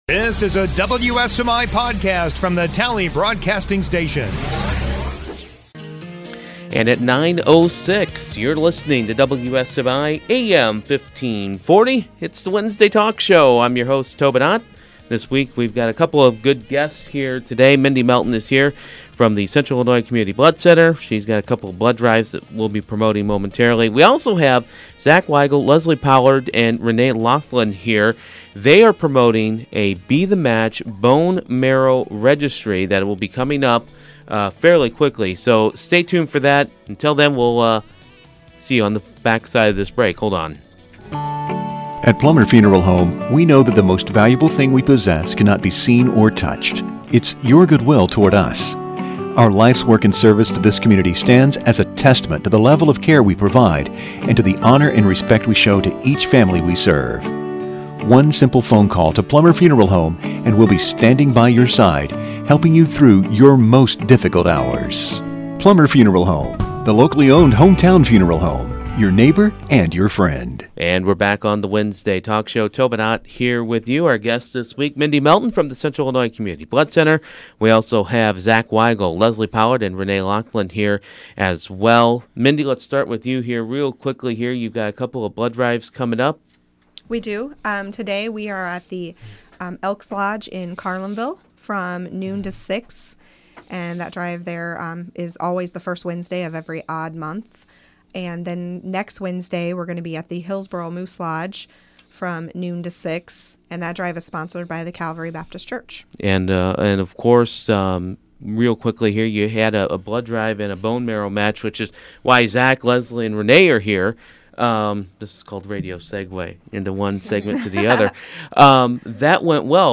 Podcasts - Wednesday Talk